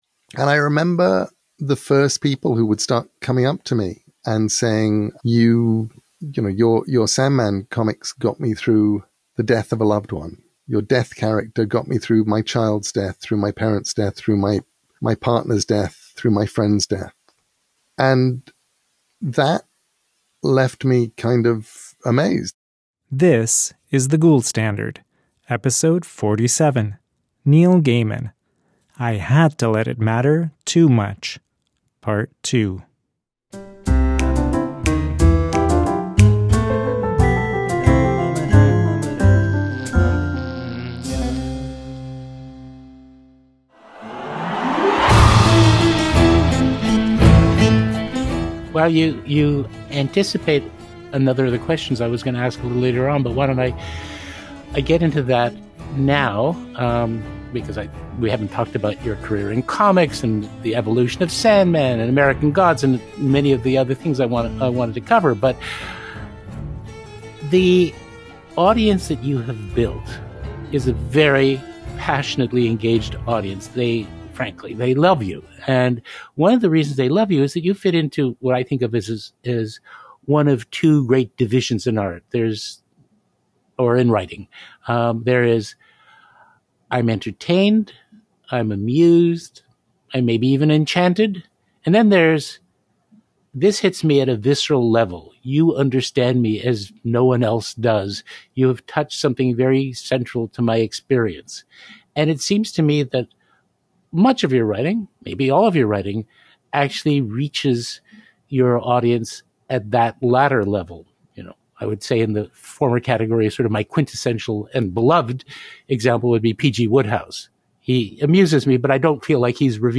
In the second instalment of The Gould Standard’s sweeping conversation with the best-selling author Neil Gaiman, we venture deeper into his extraordinary literary odyssey, navigating the fine line between artistry and entertainment. Neil shares insights into the unexpected and profound connections his creations, especially the “Sandman” and “American Gods,” have forged between him and his readers.